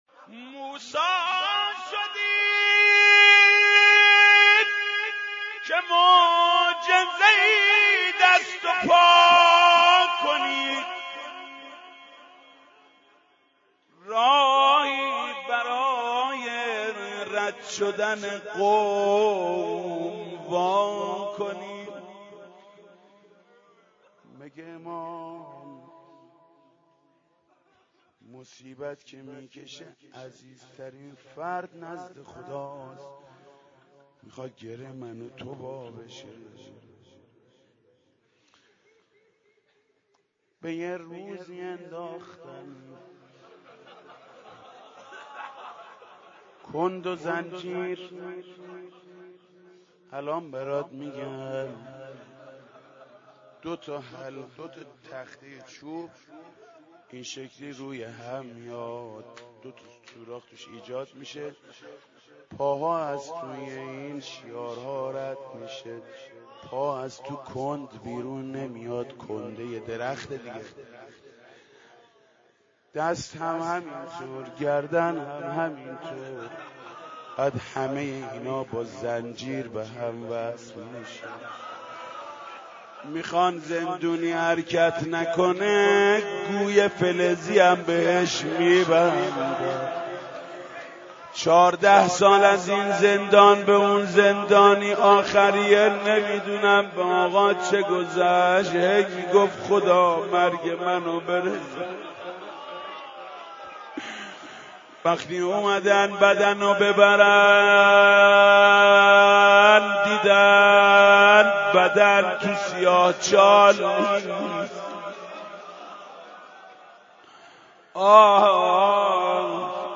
4630-attachment-روضه-جانسوز-_-باب-الحوائج-حضرت-امام-موسی-ابن-جعفر-_-حاج-محمود-کریمی.mp3